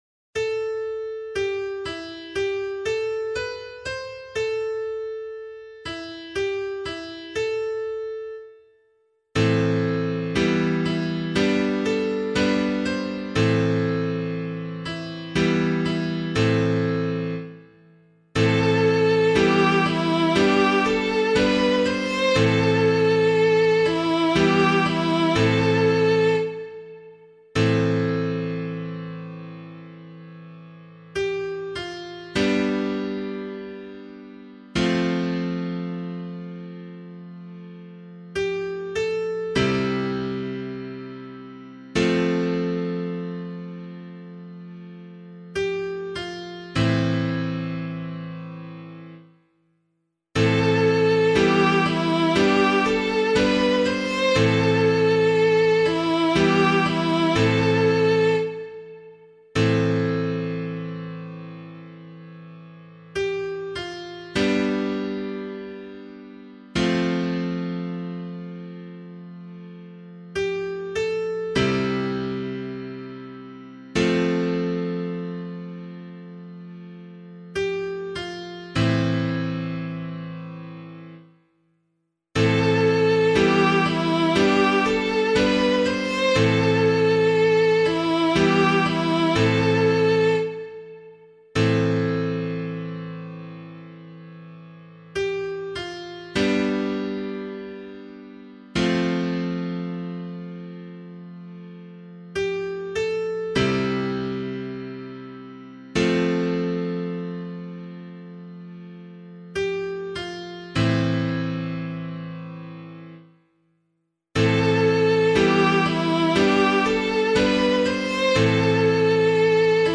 133 Presentation Psalm [LiturgyShare 4 - Oz] - piano.mp3